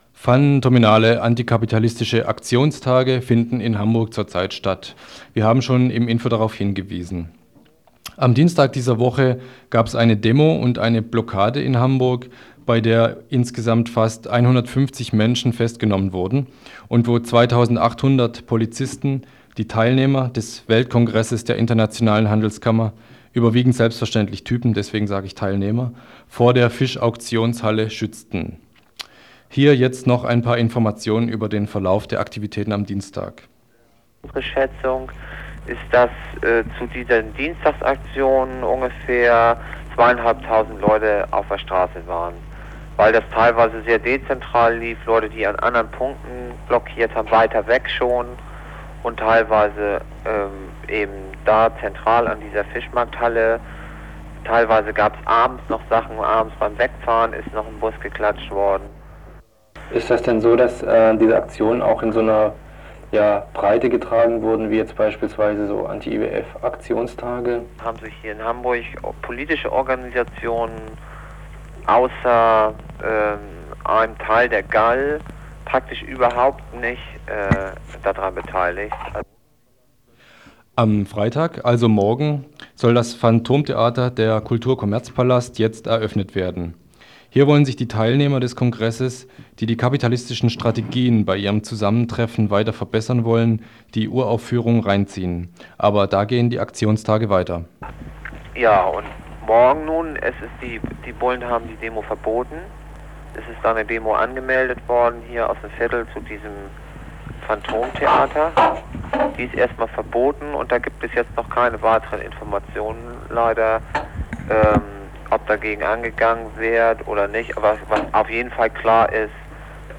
Phantominale antikapitalistische Aktionstage in Hamburg: 150 Festnahmen nach Blockaden. O-Ton aus Hamburg